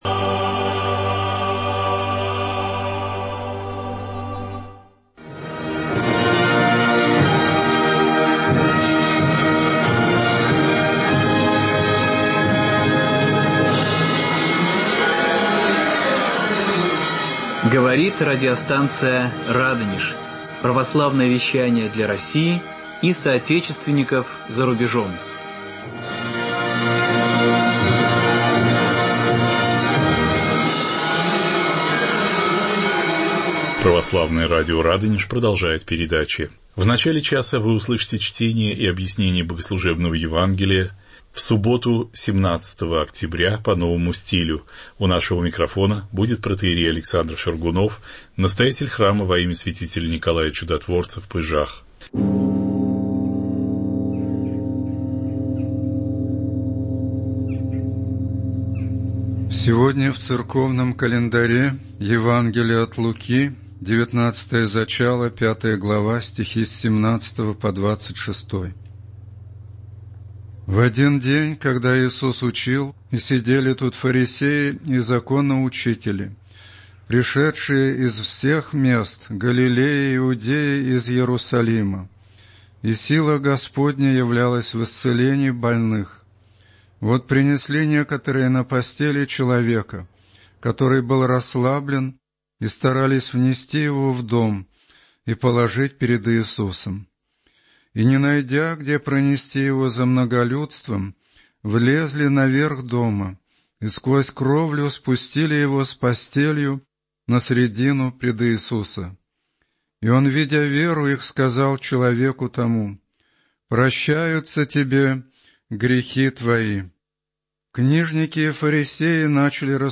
14.10.2016 20:18 Слушать Скачать MP3-архив часа Уникальная архивная запись акафиста Покрову Пресвятой Богородицы
хор